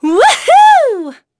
Aselica-Vox_Happy4.wav